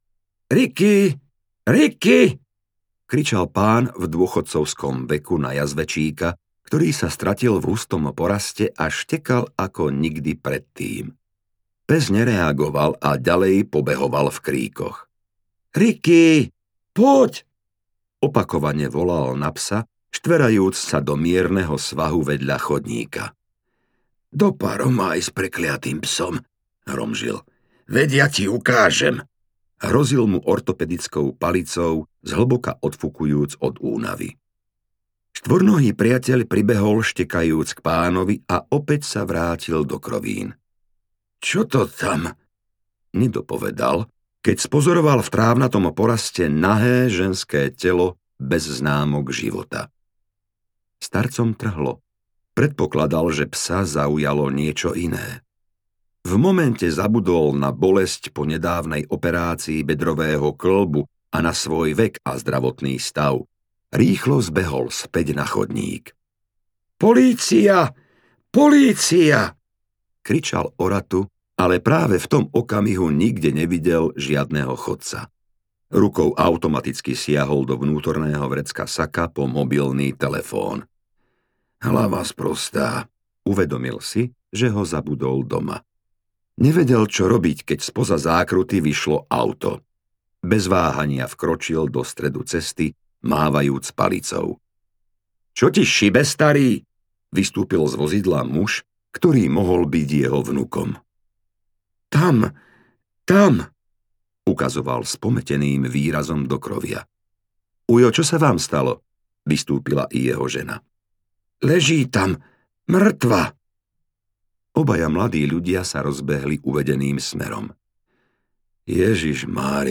Hlava ako dôkaz audiokniha
Ukázka z knihy